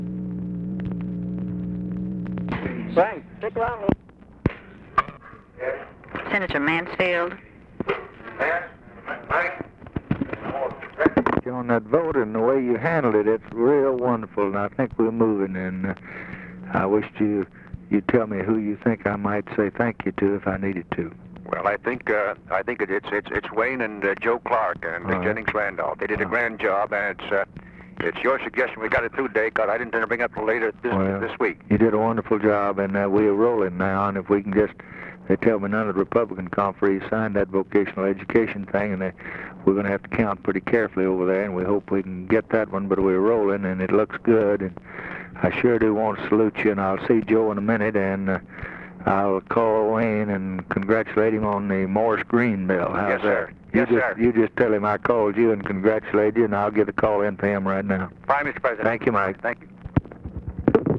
Conversation with MIKE MANSFIELD, December 10, 1963
Secret White House Tapes